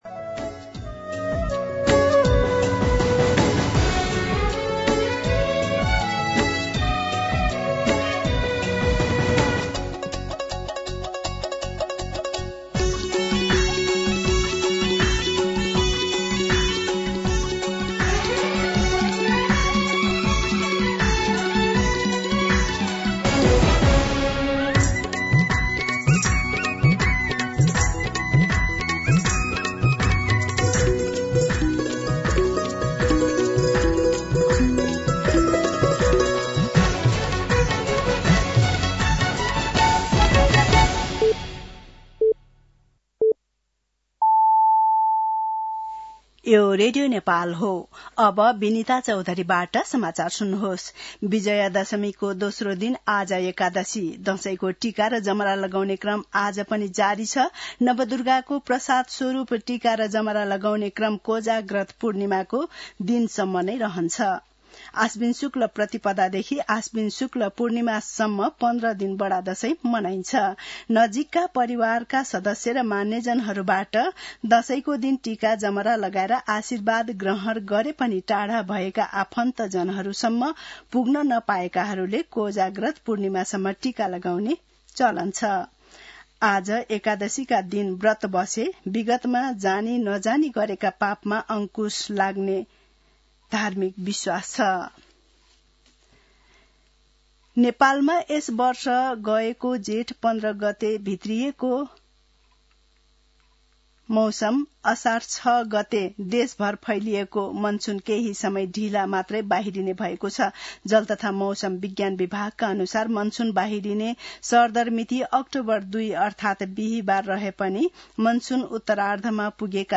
दिउँसो ४ बजेको नेपाली समाचार : १७ असोज , २०८२
4pm-News.mp3